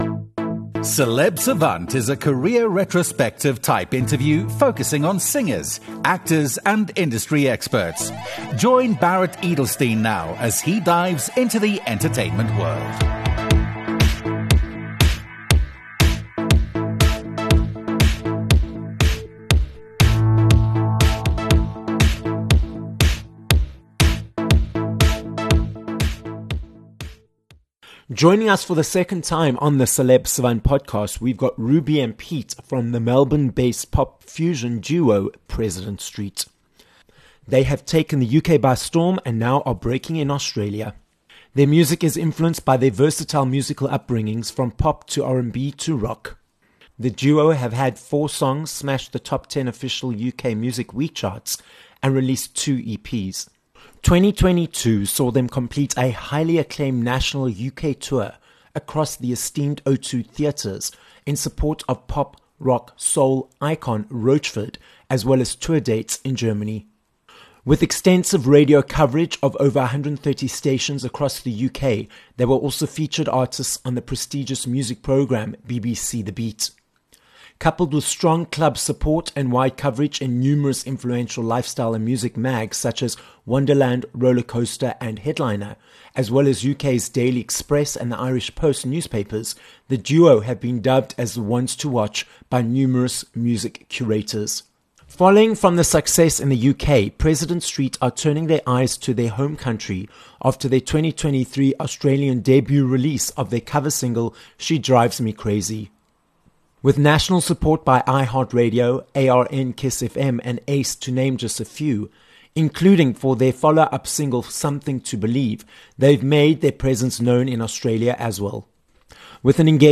28 May Interview with President Street